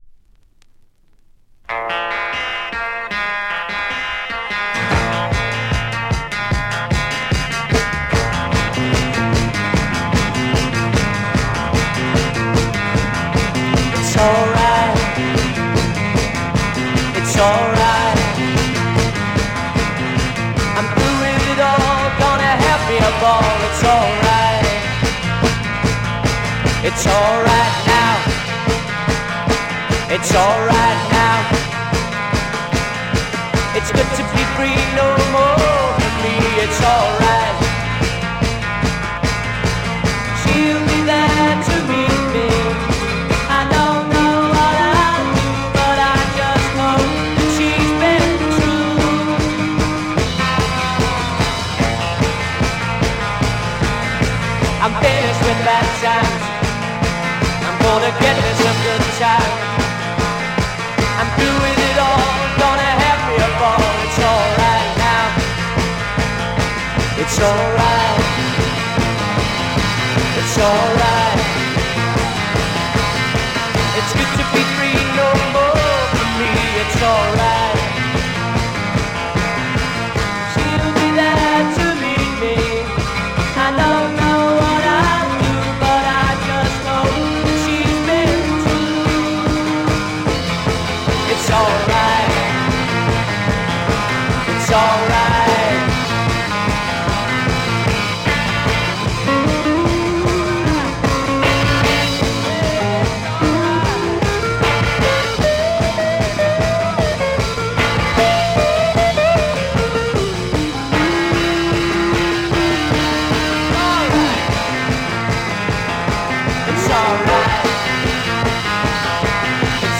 Classic Freakbeat garage mod French pic